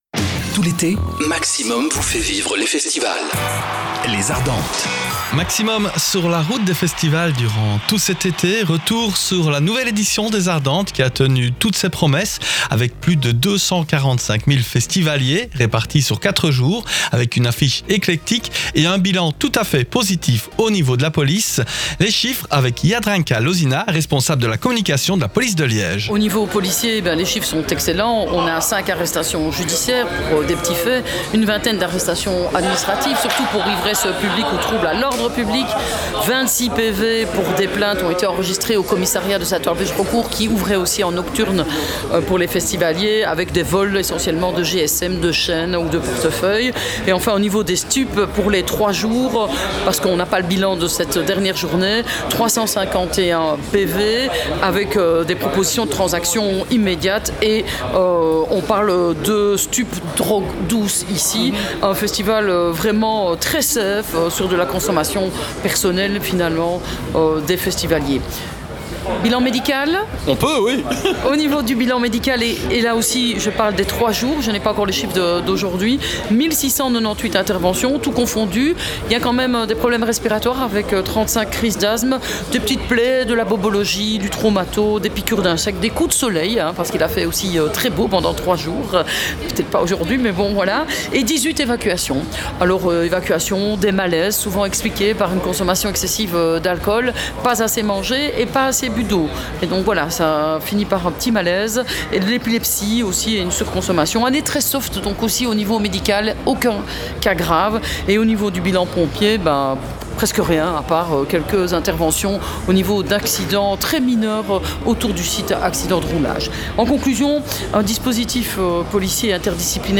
Cette année, c’était le grand retour de Puggy aux Francofolies de Spa. Rencontre avec Matthew Irons, Romain Descampe et Egil "Ziggy" Franzén.